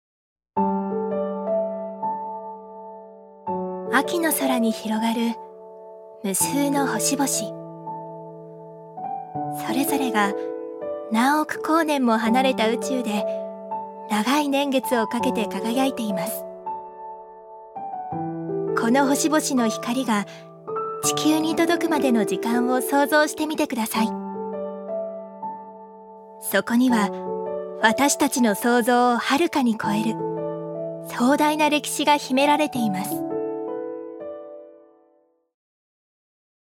女性タレント
音声サンプル
ナレーション２